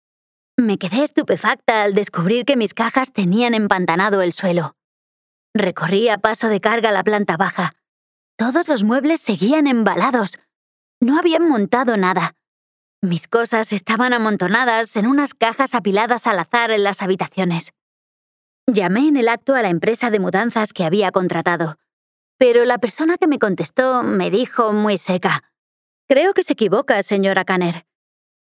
Female
Bright, Cheeky, Children, Cool, Corporate, Friendly, Natural, Warm, Witty, Versatile, Young
Spanish (Castilian, Andalusian)
Microphone: Neumann TL 103